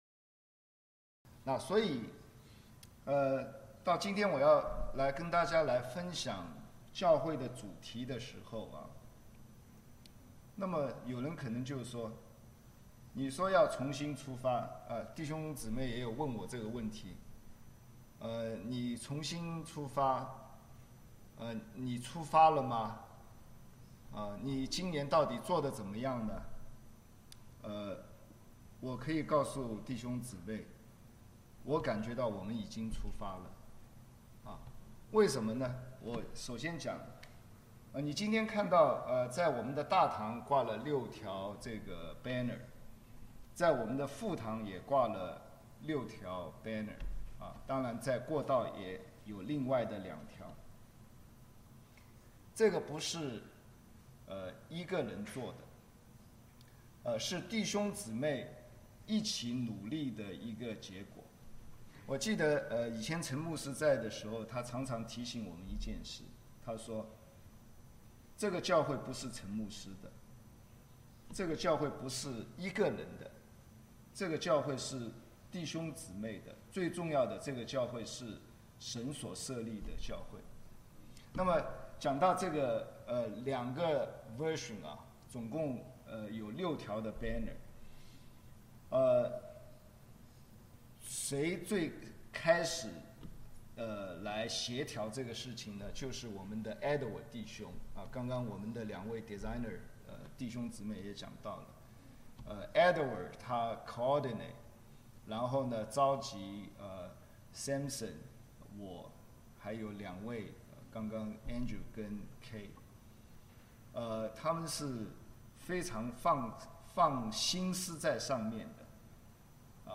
路加福音 5:1-11 Service Type: 三堂聯合崇拜 - 國語 5:1 耶穌站在革尼撒勒湖邊、眾人擁擠他、要聽 神的道。